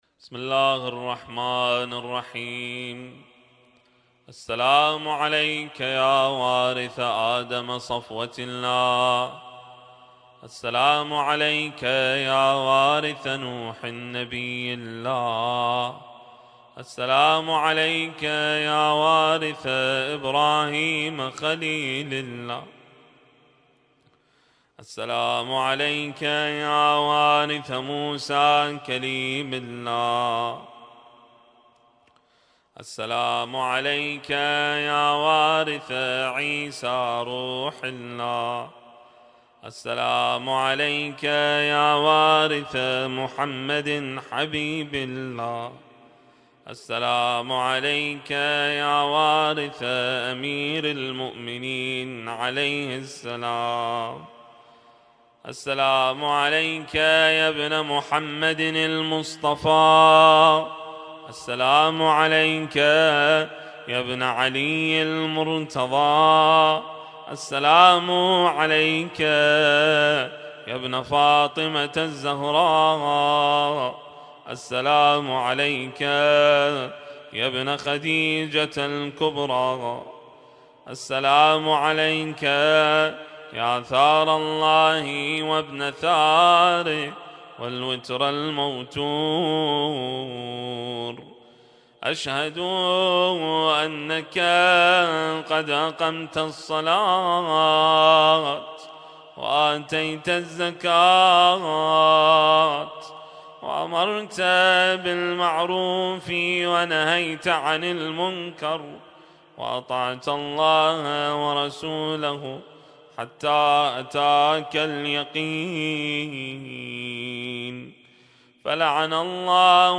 القارئ: الرادود